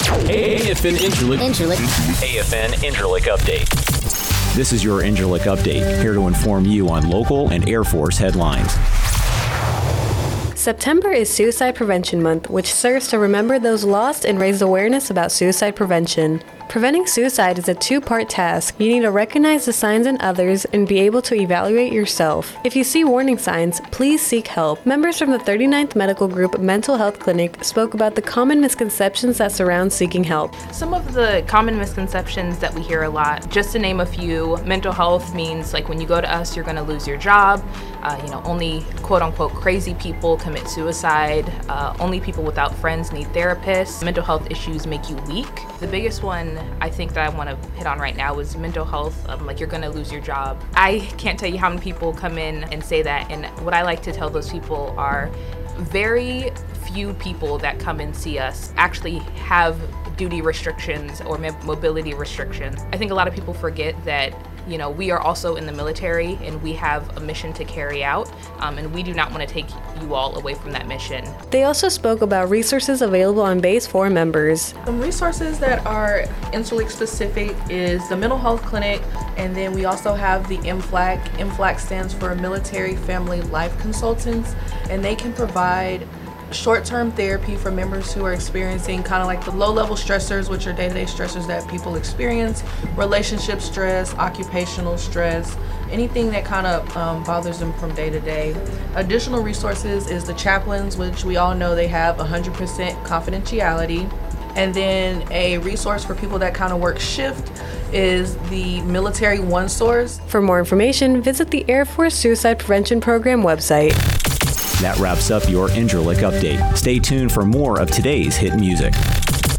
American Forces Network Incirlik reports on National Suicide Prevention Month with members form the 39th Medical Group Mental Health Clinic discussing stigmas surrounding seeking help as well as available resources on base on Sep. 19, 2024, at Incirlik Air Base, Türkiye. (Defense Media Activity radio newscast